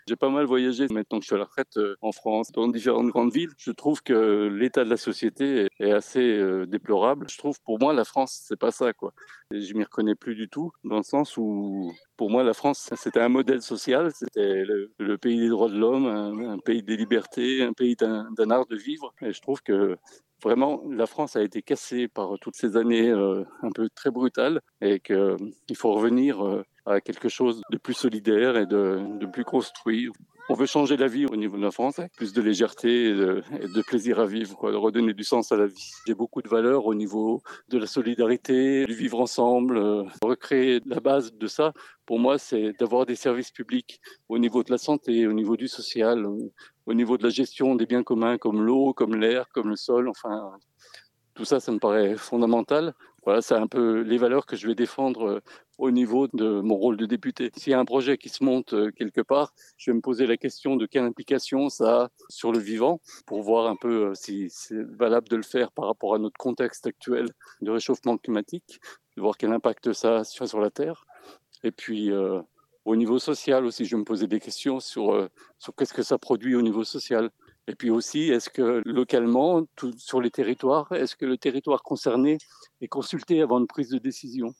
Voic les interviews des 5 candidats de cette 3eme circonscription de Haute-Savoie (par ordre du tirage officiel de la Préfecture) et tous les candidats en Haute-Savoie et en Savoie.